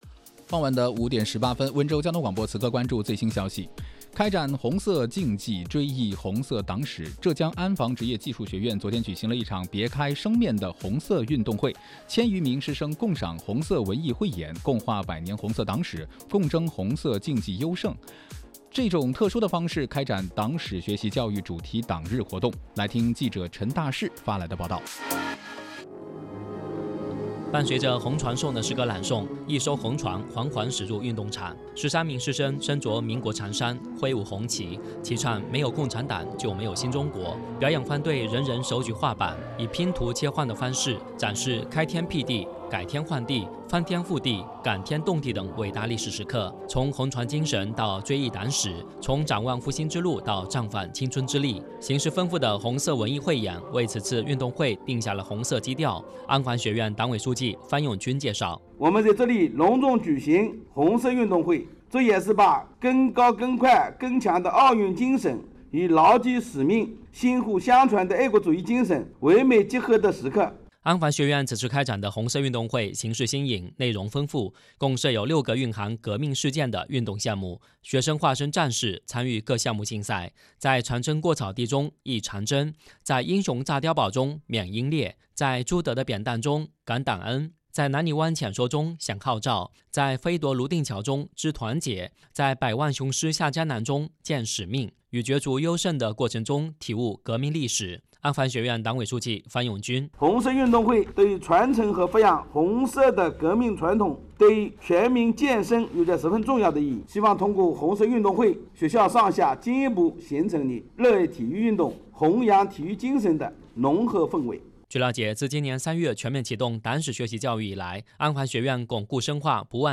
广播消息：安防学院举行红色运动会（温州交通广播播出）.mp3